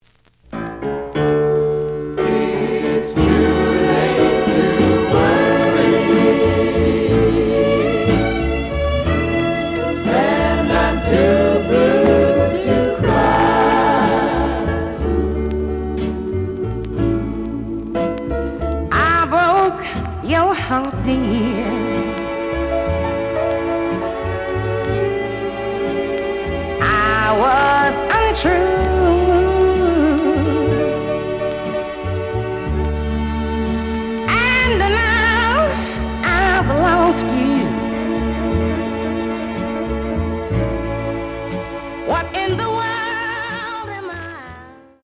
The audio sample is recorded from the actual item.
●Genre: Vocal Jazz
Looks good, but slight noise on A side.)